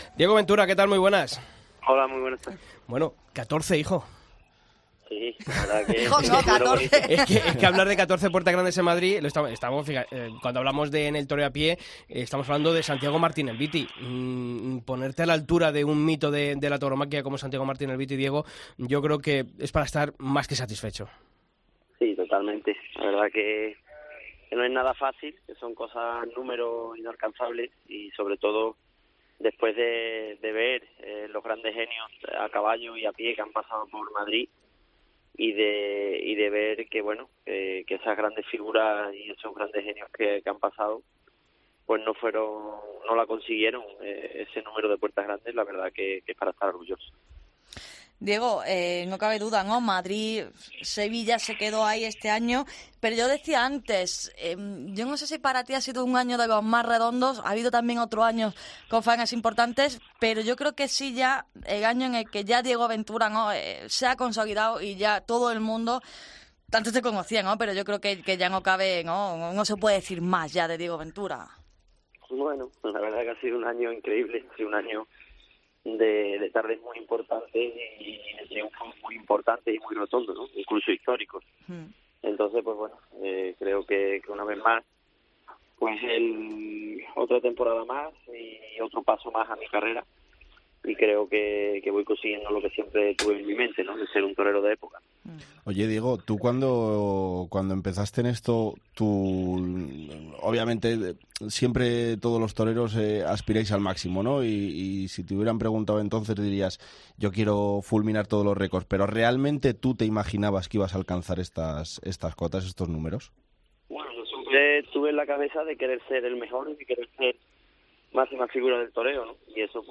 Escucha la entrevista a Diego Ventura en El Albero